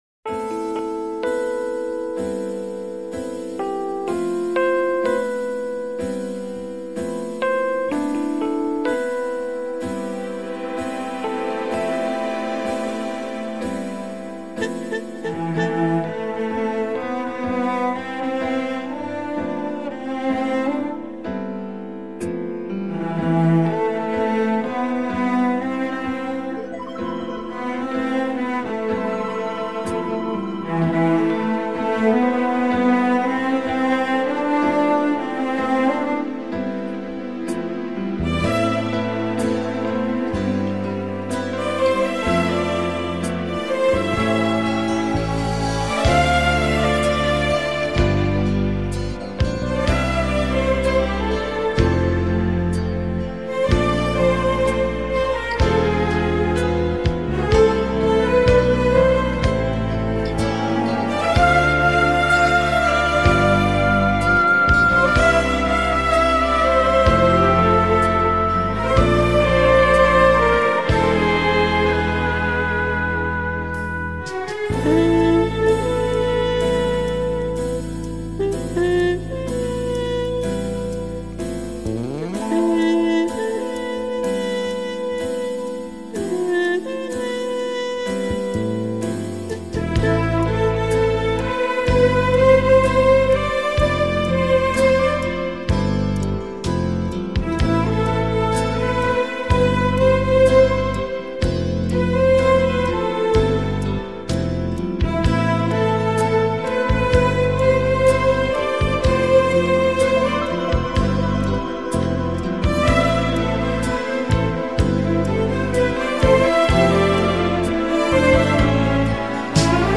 浪漫弦乐和流行风格的完美融合 精装6CD超值价答谢乐迷